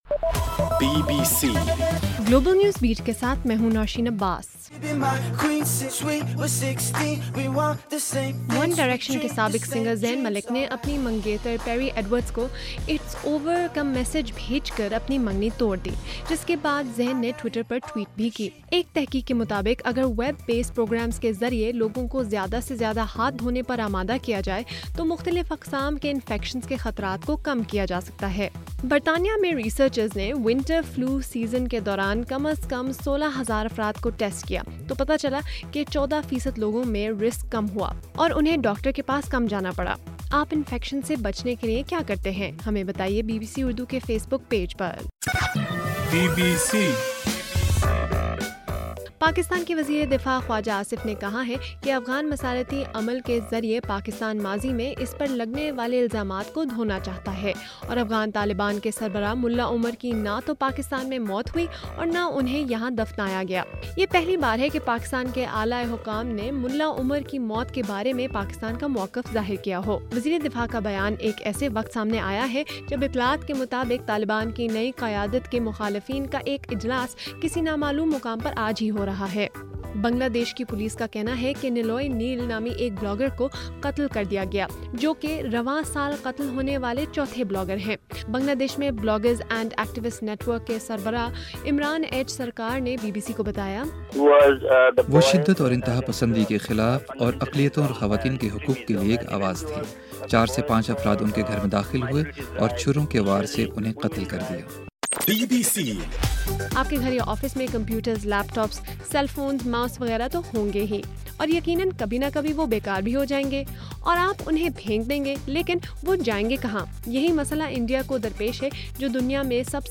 اگست 7: رات 9 بجے کا گلوبل نیوز بیٹ بُلیٹن